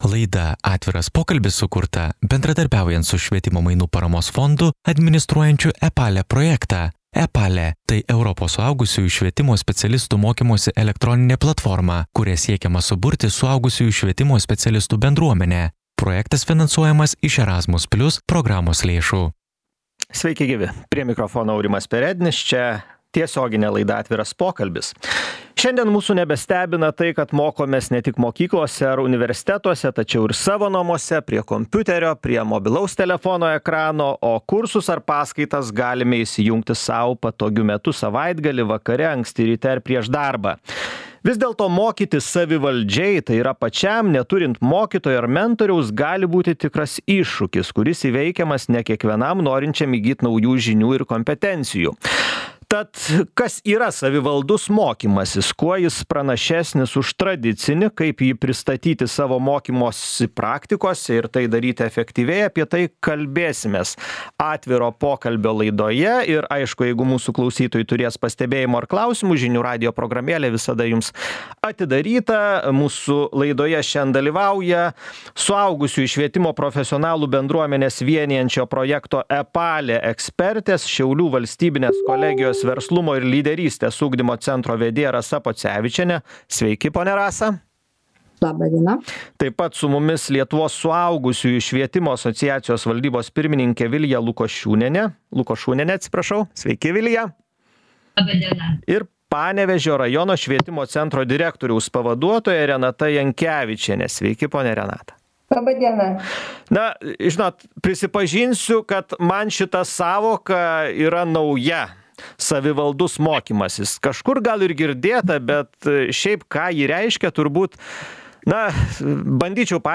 Apie tai kalbamės su suaugusiųjų švietimo profesionalų bendruomenę vienijančio projekto „Epale“ ekspertėmis